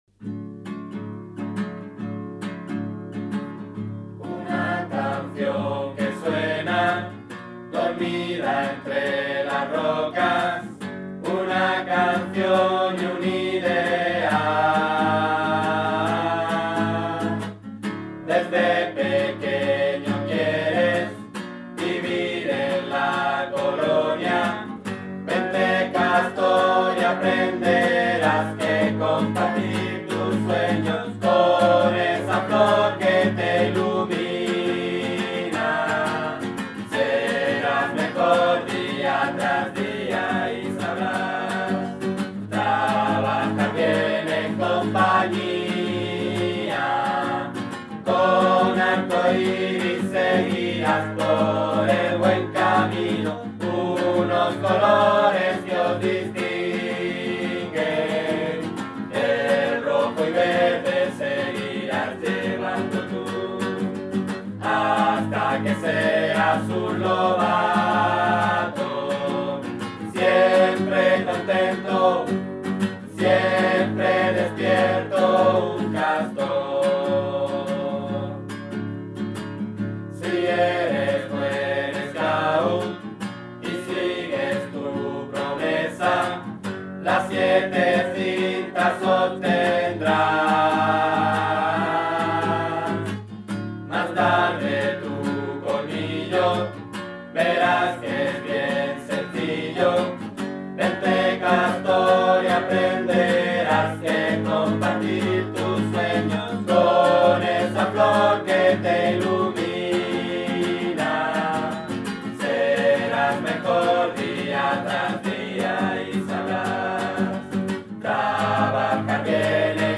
Grupo Scout Poseidón 304